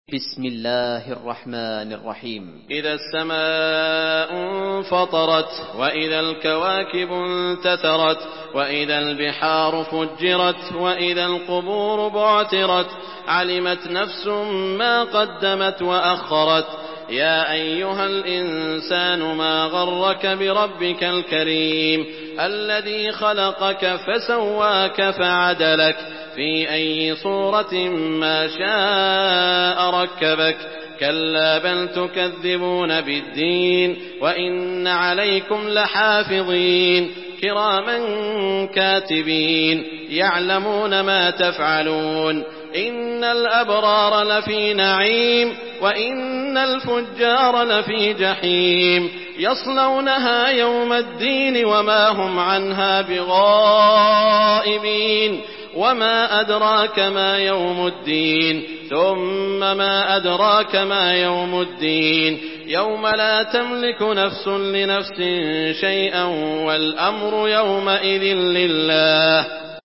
Surah الانفطار MP3 in the Voice of سعود الشريم in حفص Narration
Surah الانفطار MP3 by سعود الشريم in حفص عن عاصم narration. Listen and download the full recitation in MP3 format via direct and fast links in multiple qualities to your mobile phone.
مرتل حفص عن عاصم